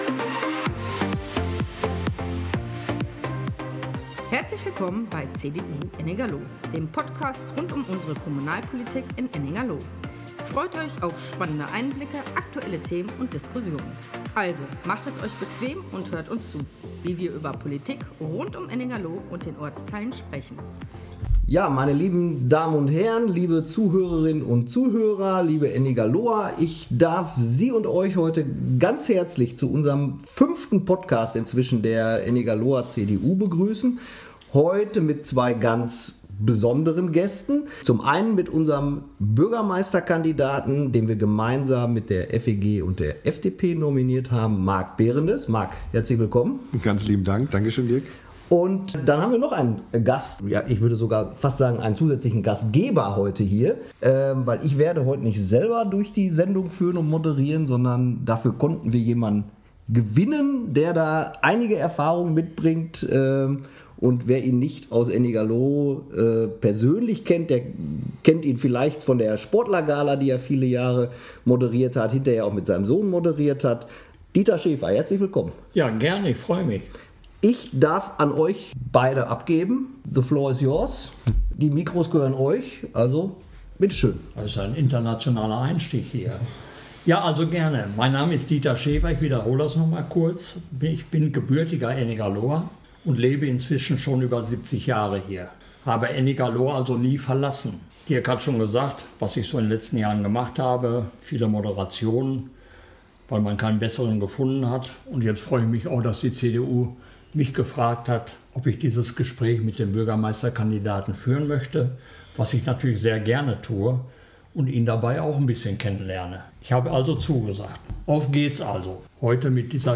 Lively-Instrumental Intro und Outro